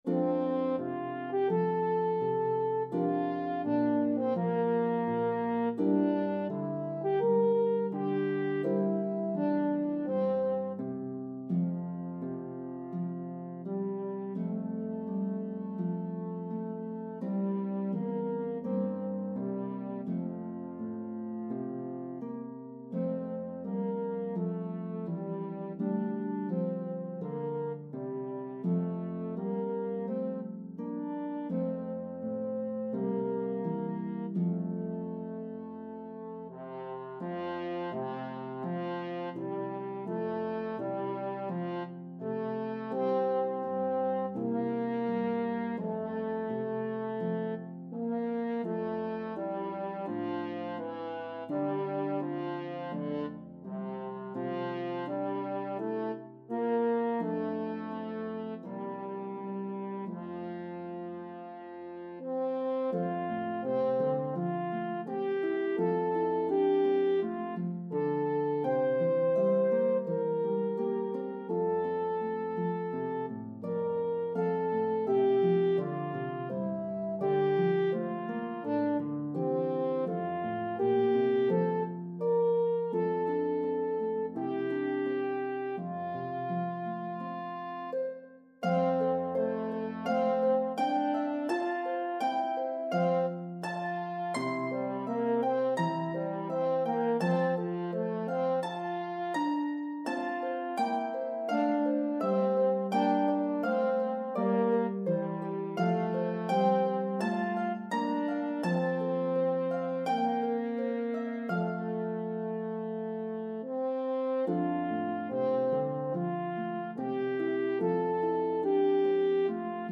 Harp and French Horn version